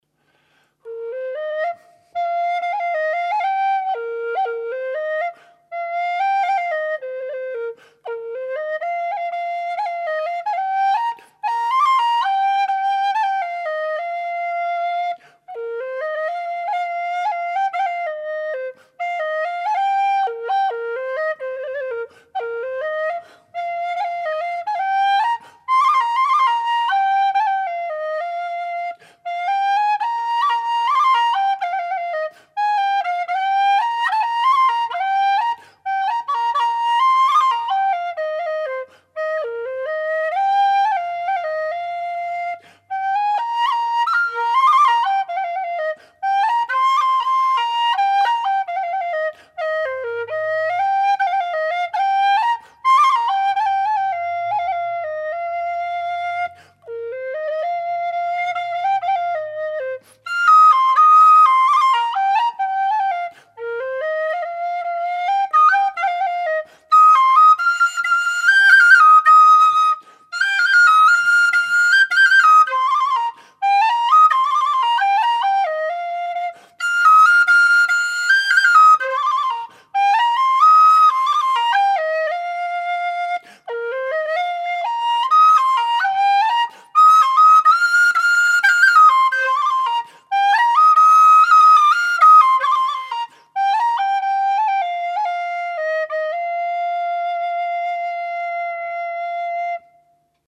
Bb whistle - 145 GBP
made out of thin-walled aluminium tubing with 15.7mm bore
Audio:Bb-impro-1.mp3:) (Improvisation)